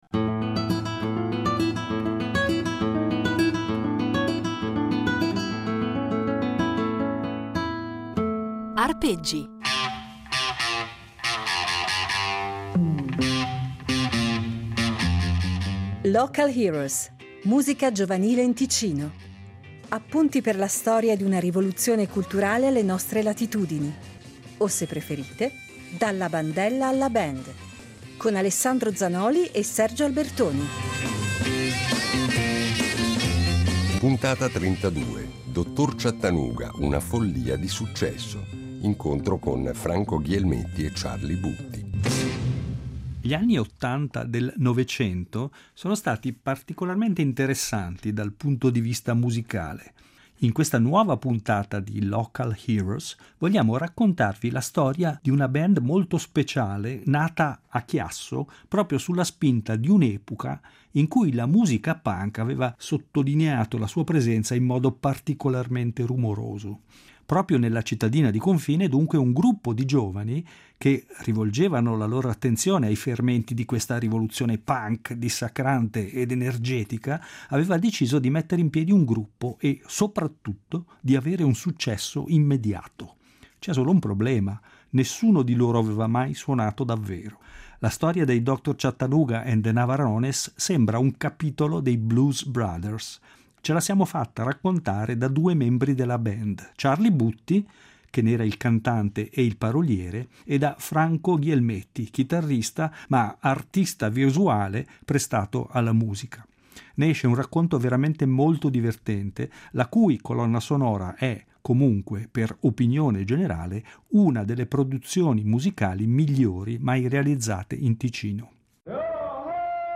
In ognuna delle puntate di “Local Heroes” affronteremo un tema diverso, e daremo la parola a un ospite diverso, ma ascolteremo anche molta musica. Musica piena di energia ed entusiasmo, che vale sicuramente la pena di far risuonare, a distanza di decenni.